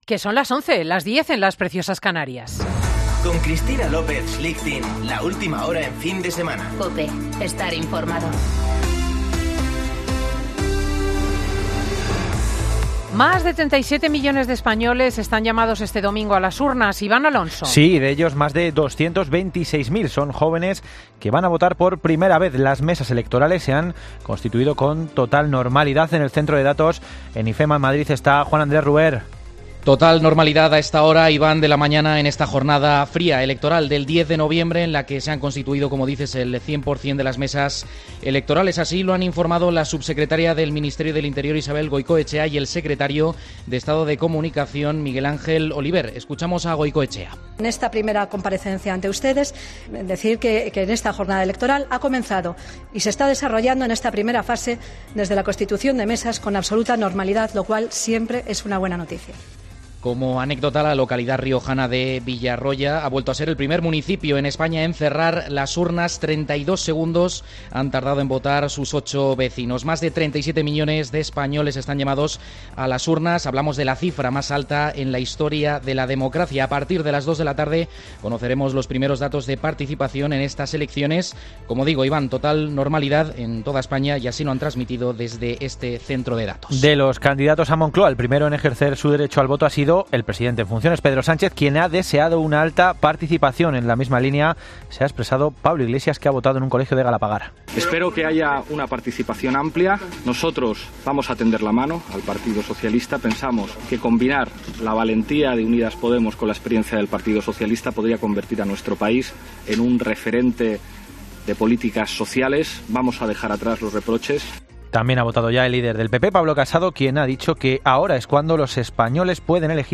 Boletín de noticias COPE del 10 de noviembre de 2019 a las 11.00 horas